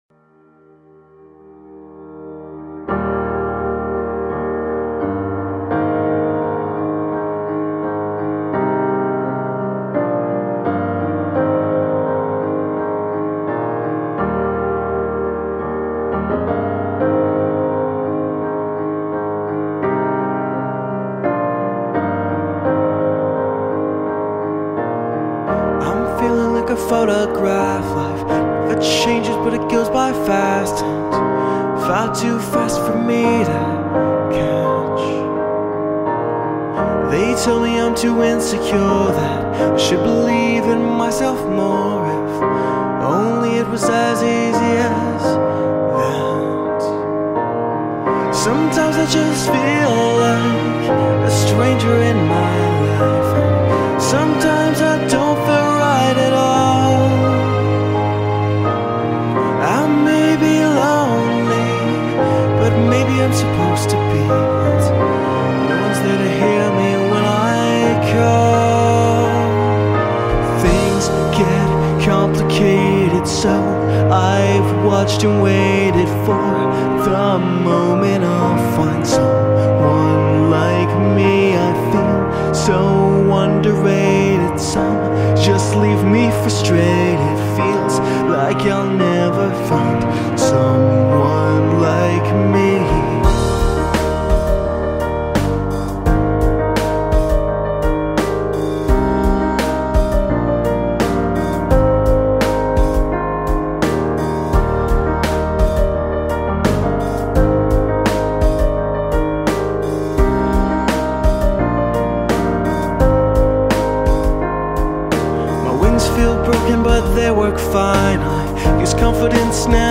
About time I did a non-depressing nice soft ballad!